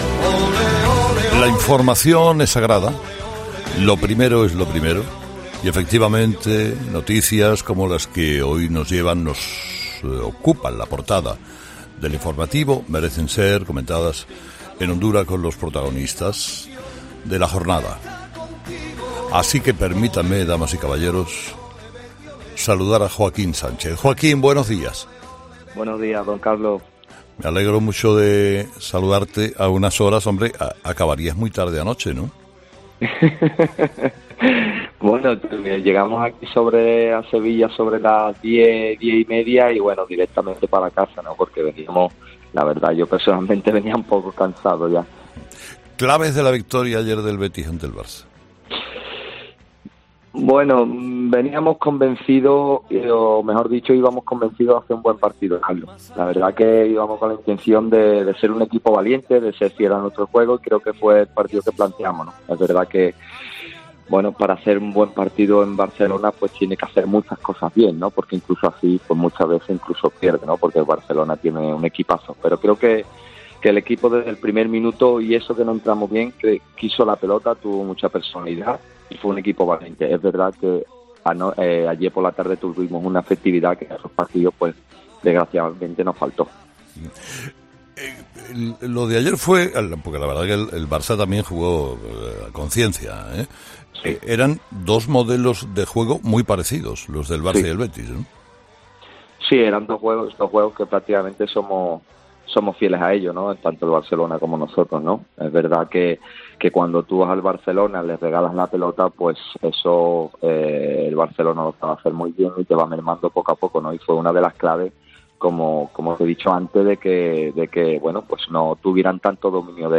Sin duda junto a los miles de béticos que han celebrado el triunfo de su equipo, el más eufórico es Carlos Hererra, director de ‘Herrera en COPE’ quien no ha dudado en festejarlo y compartir su felicidad con todos sus oyentes.
El bético no ocultaba su felicidad ante los elogios de Herrera por el gol que marcó.